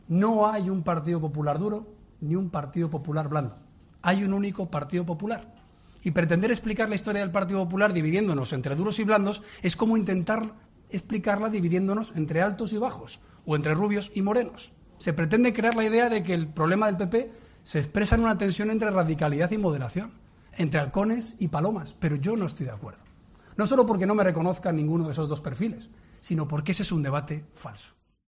En su intervención ante la Junta Directiva Nacional del PP, ha terminado recomendando a todos los presentes, barones regionales y cargos del partido: "Pensemos en grande y miremos lejos porque más pronto que tarde nos encargarán el futuro de España".